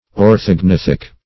Orthognathic \Or`thog*nath"ic\, a.